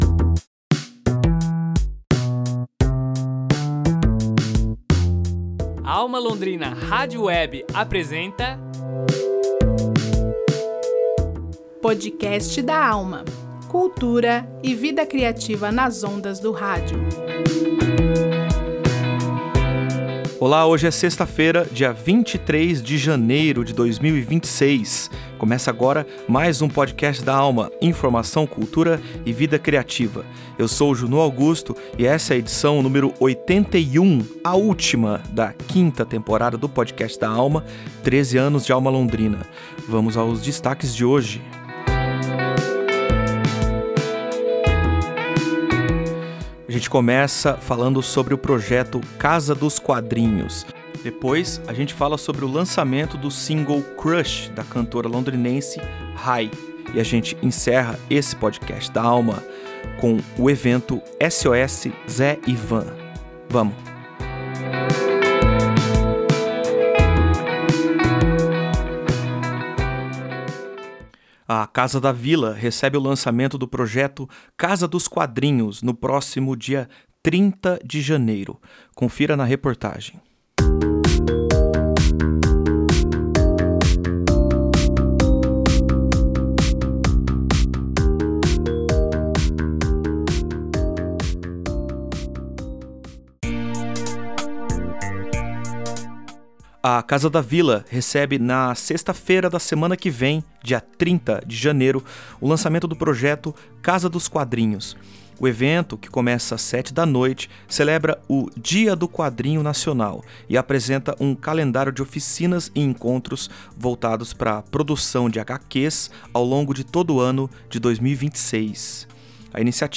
Jornalismo Cultural